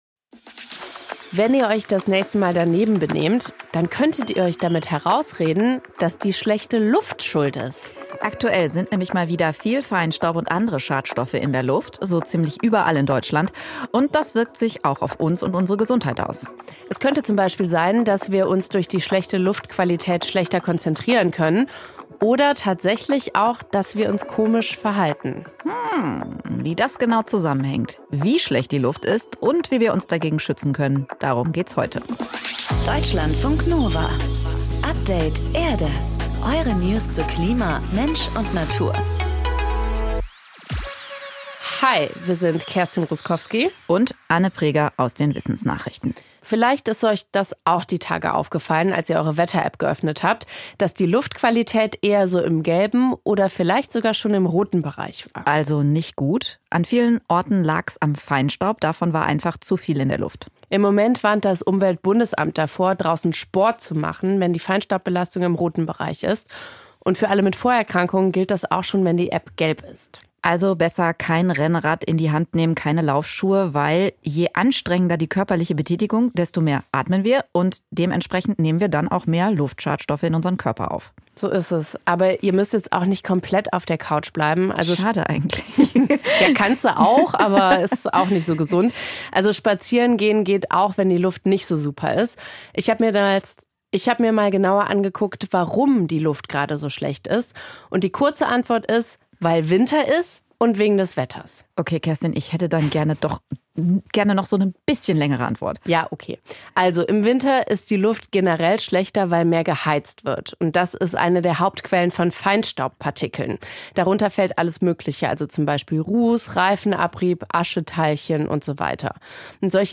Wir haben mit Leuten gesprochen, die trotz allem Optimismus verbreiten.
Natursound: Spaziergang im Schnee